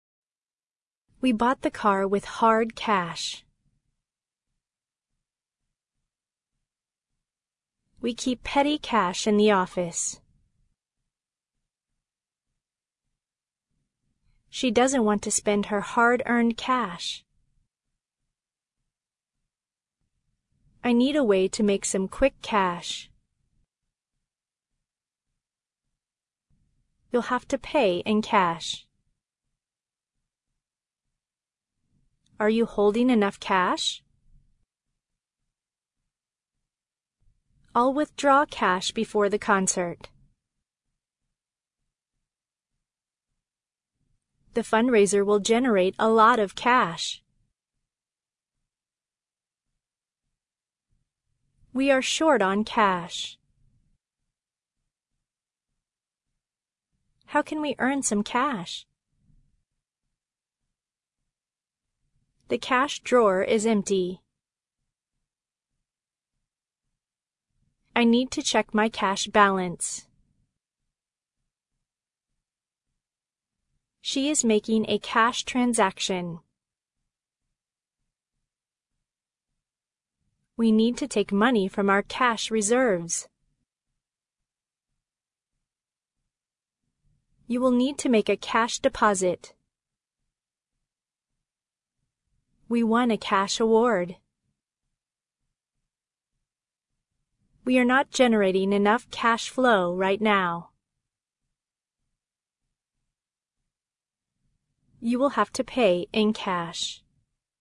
cash-pause.mp3